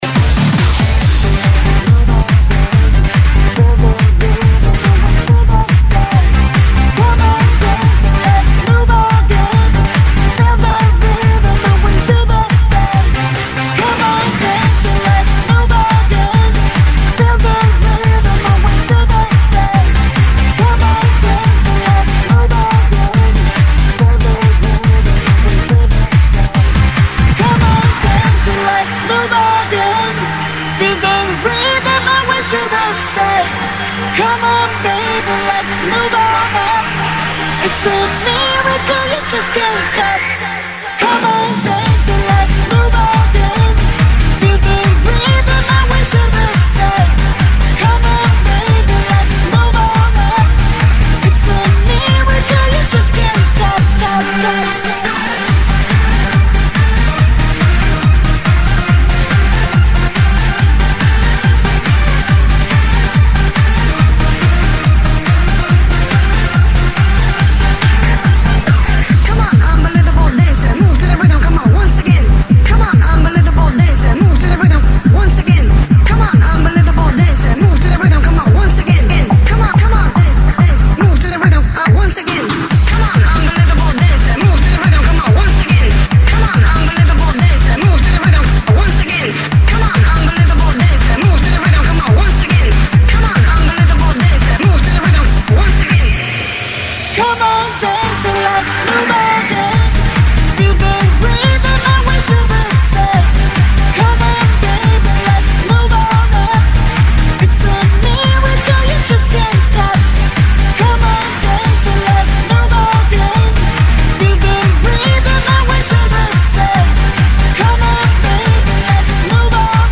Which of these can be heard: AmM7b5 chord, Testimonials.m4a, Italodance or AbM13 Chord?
Italodance